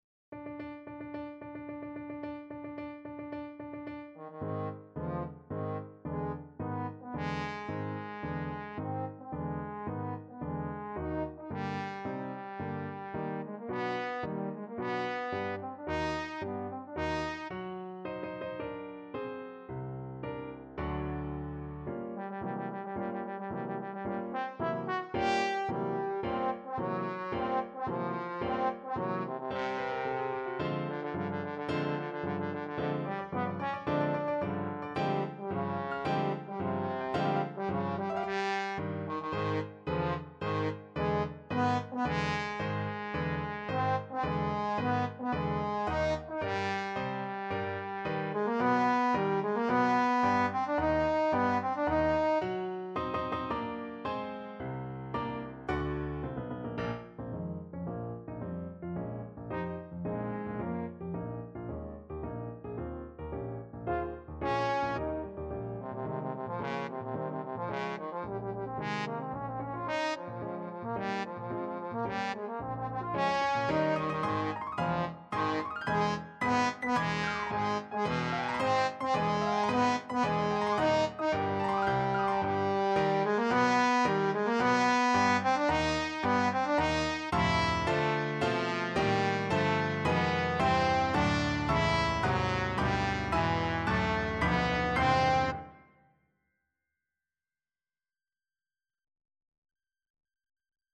4/4 (View more 4/4 Music)
~ = 110 Tempo di Marcia
Classical (View more Classical Trombone Music)